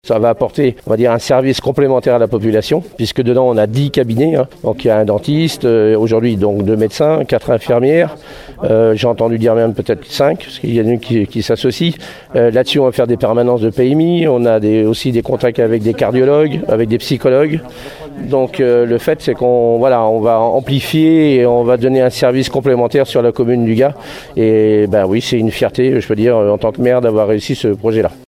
On écoute Patrice Brouhard, maire du Gua et président de la Communauté de communes du Bassin de Marennes :